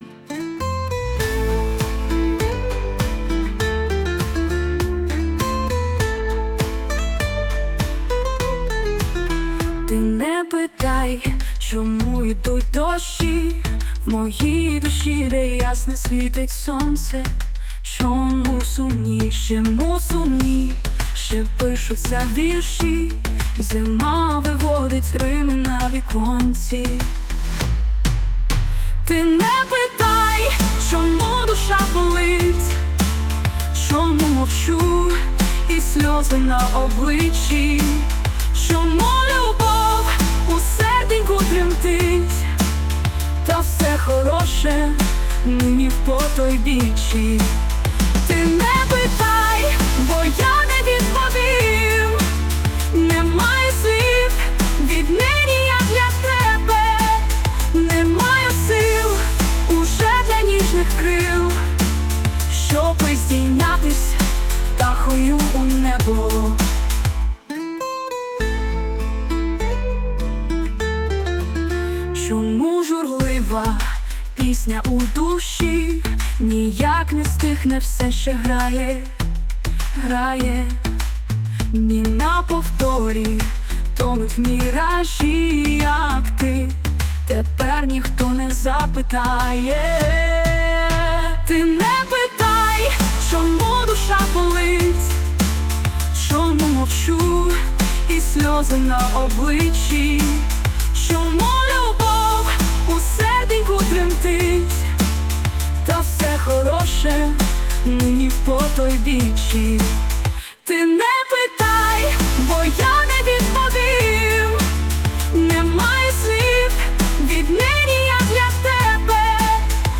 голос  та музика штучного інтелекту
СТИЛЬОВІ ЖАНРИ: Ліричний